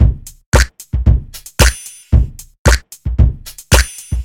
A typical Timbo rhythm would involve a closed and open Hi-Hat. Below is an example of what Hi-Hat rhythm would work with our drum pattern so far.
Drums_Mix_Example_1.mp3